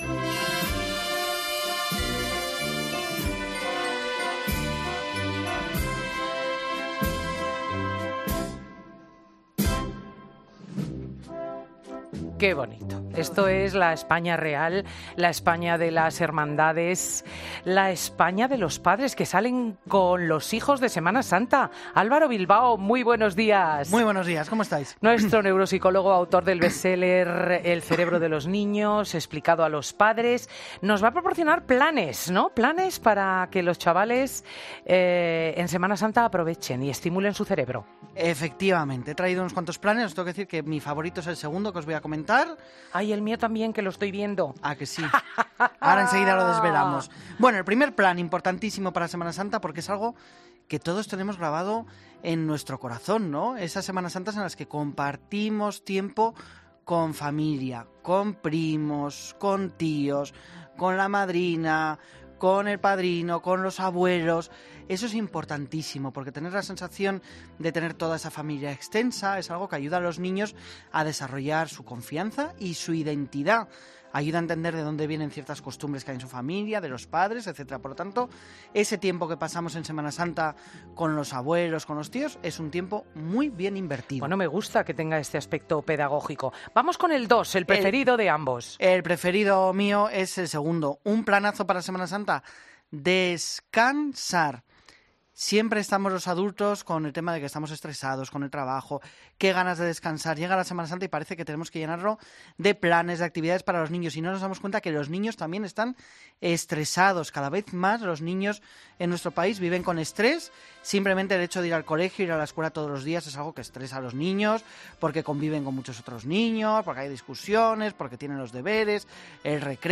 Siempre, de la mano y la voz de Cristina López Schlichting, en cuyo dilatado currículum vitae se incluyen sus labores de articulista y reportera en los principales periódicos de España (ABC, El Mundo o La Razón o su papel de tertuliana de televisión.